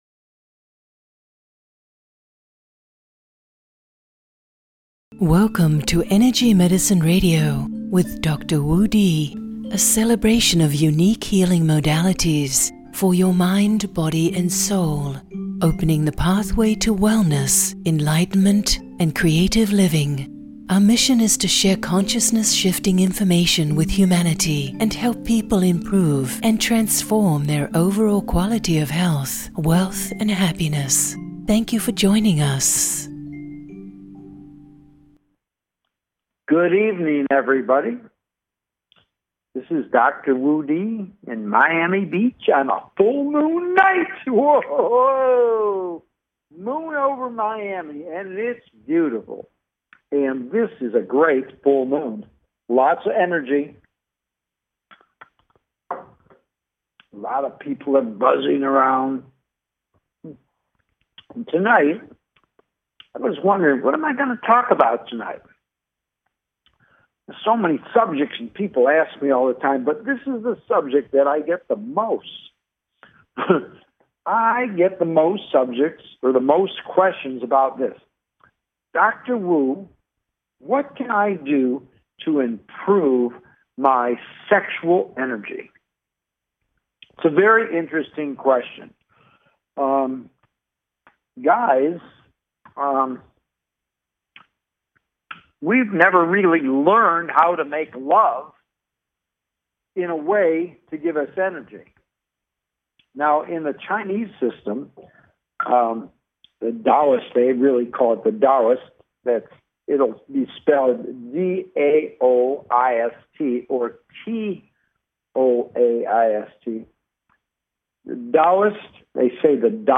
Live on Air: Every 1st and 3rd Tuesday 8-9pm EST (5-6pm PST) USA Join me as I support expansion into your full potential while paving the way to profound transformation and healing.